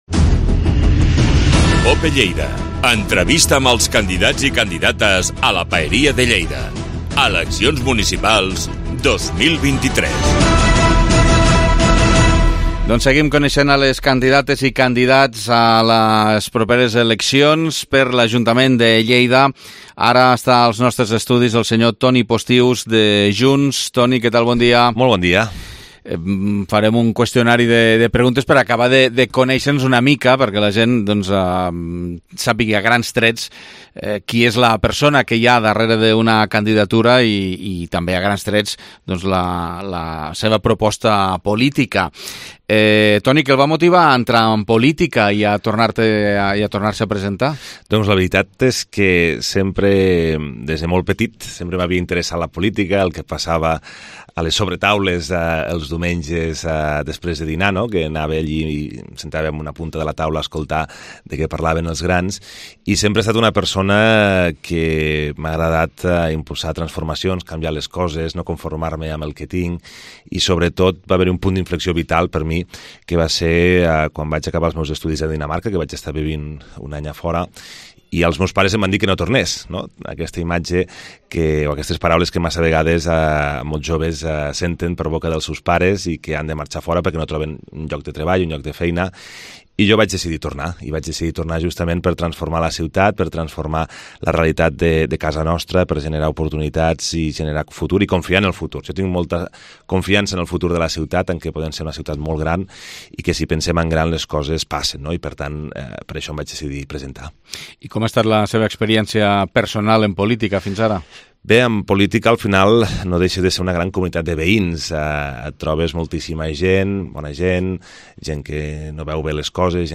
Entrevista Campanya Electoral 2023 - Toni Postius - Junts